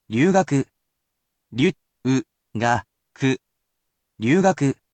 If you cannot read Japanese, or if you simply need a way to encode it into your memory, I will read each word aloud to assist you with pronunciation.